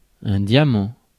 Ääntäminen
France: IPA: [ɛ̃ dja.mɑ̃]